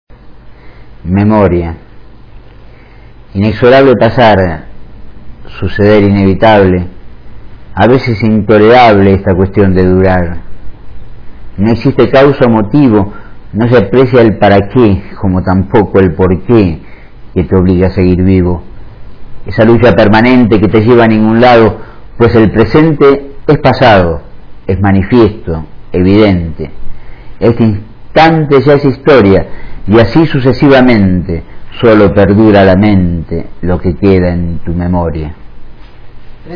Recitado por el autor (0:36", 142 KB)